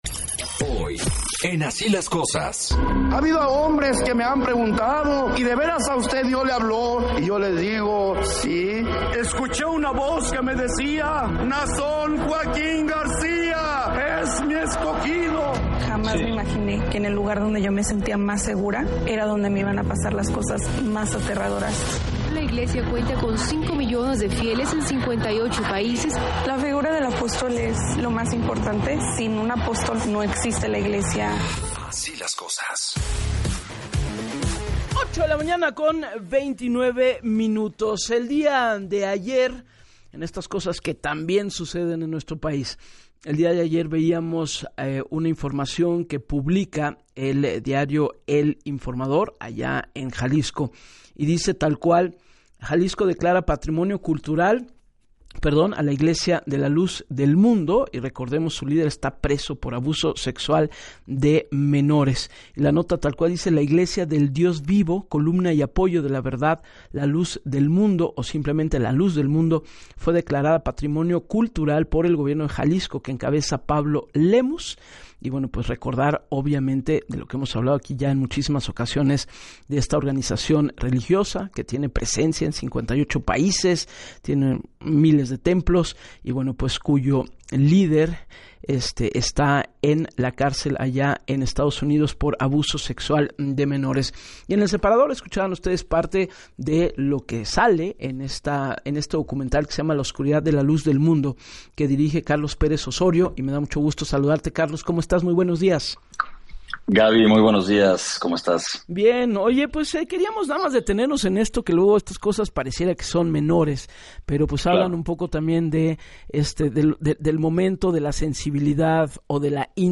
En el espacio de “Así las Cosas” con Gabriela Warkentin, detalló que la sede internacional de la iglesia que está en Jalisco, es un edificio muy bonito… recinto es impresionante, mide casi 80 metros se eleva en una comunidad de cientos de devotos, por dentro está lleno de vitrales con un bello espectáculo, hay visitas guiadas para estudiantes de arquitectura, cuenta con muchos pasajes interiores”, pero estos son parte del sitio en donde se cometían abusos.